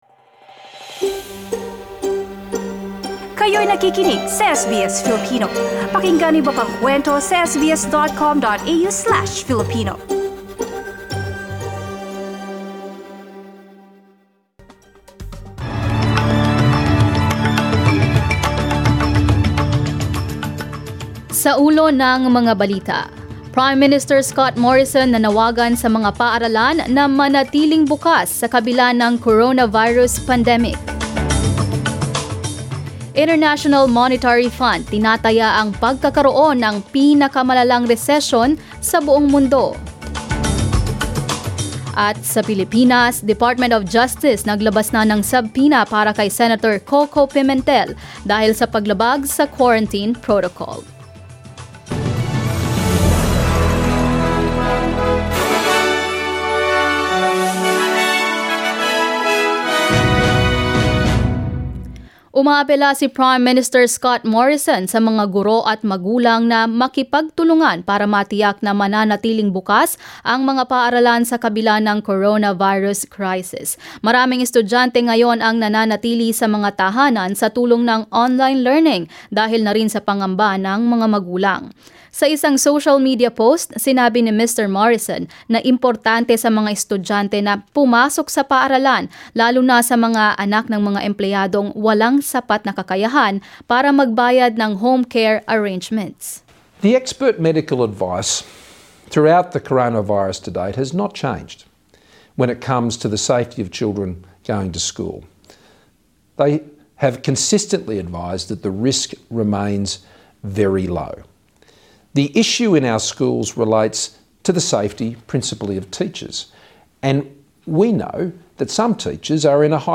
SBS News in Filipino, Wednesday 15 April